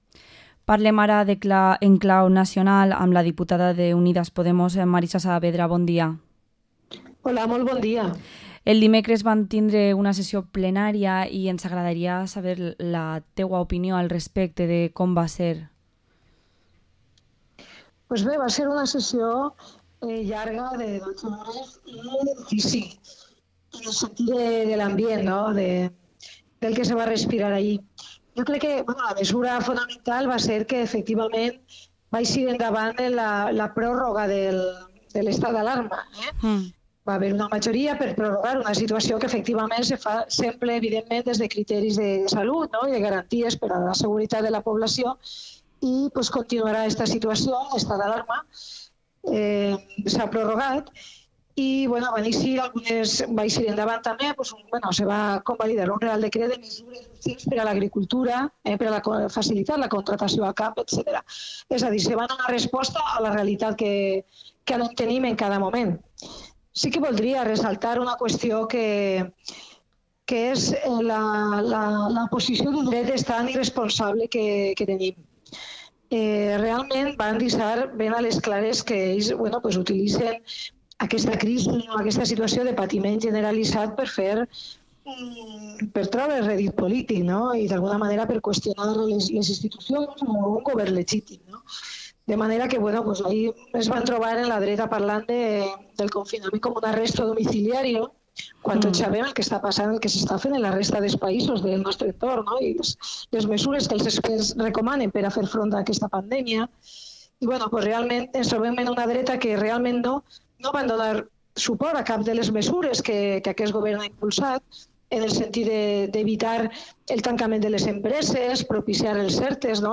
Entrevista a la diputada nacional de Unidas Podemos, Marisa Saavedra